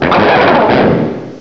cry_not_gurdurr.aif